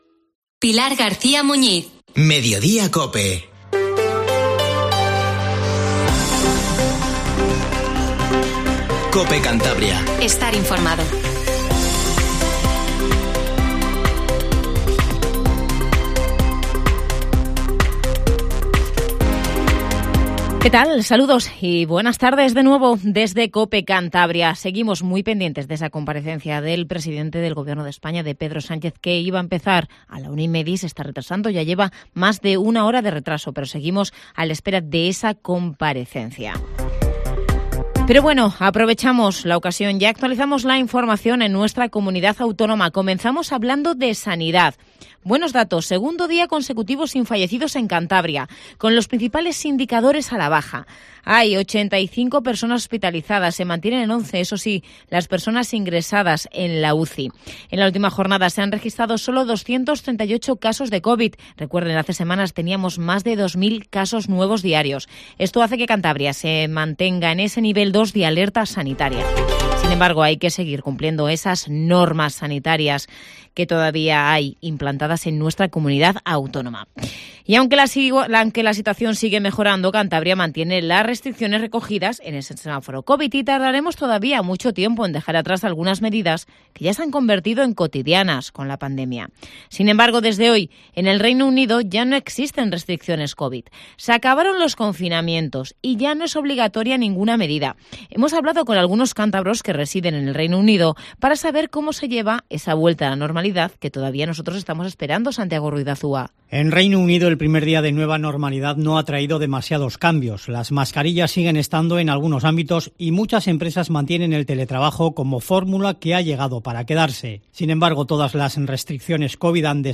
Informativo Medeiodía COPE CANTABRIA